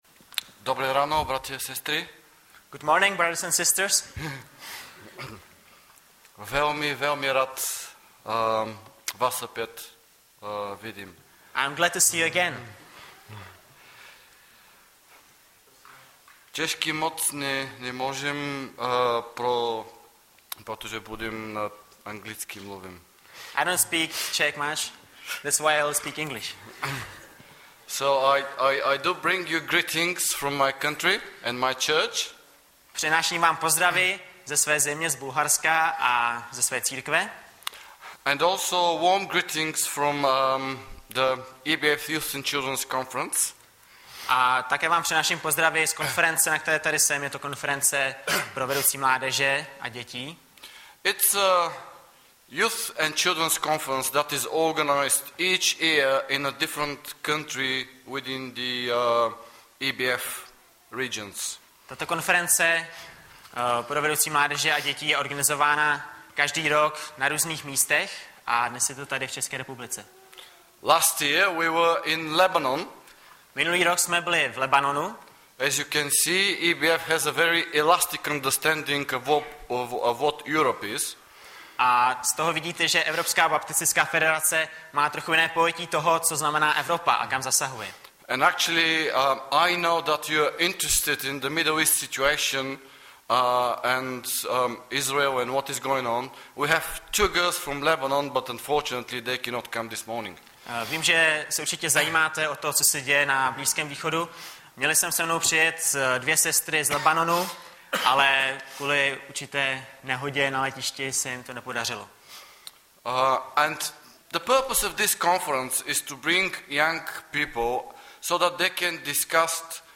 Webové stránky Sboru Bratrské jednoty v Litoměřicích.
Audiozáznam kázání